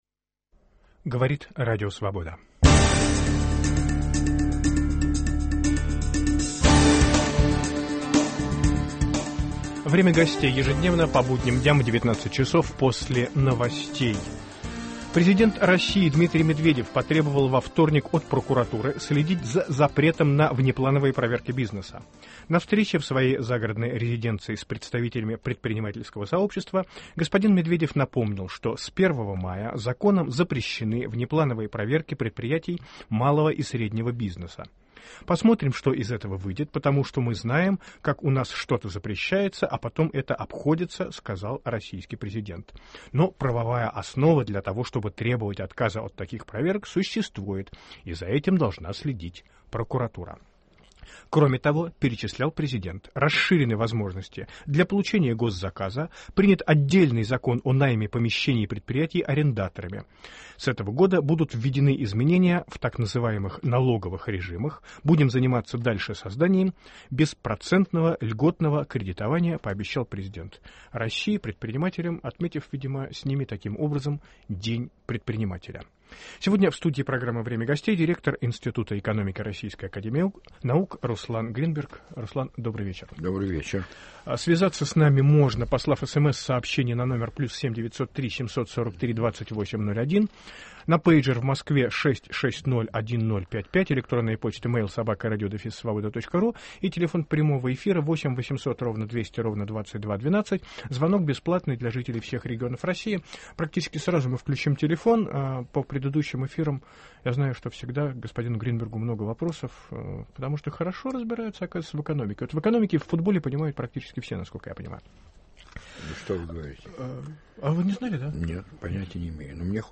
В программу приглашен директор института экономики Российской Академии наук Руслан Гринберг.